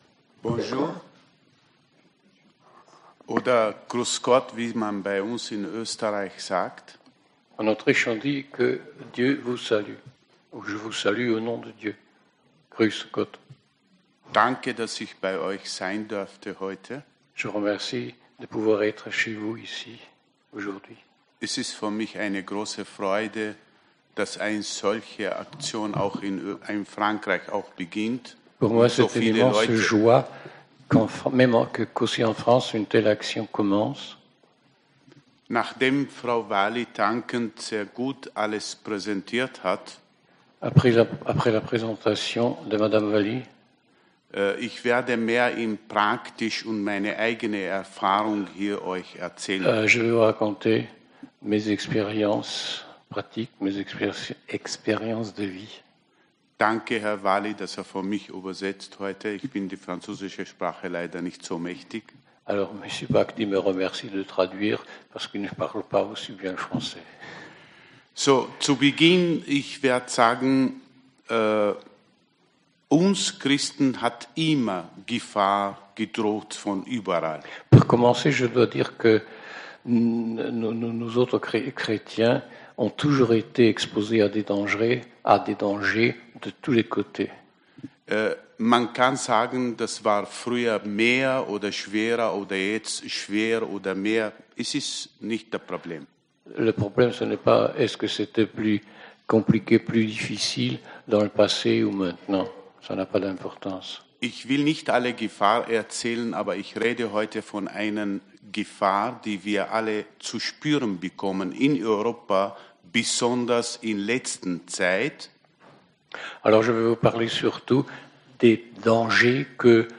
Forum des 2 & 3 février 2019 - PARIS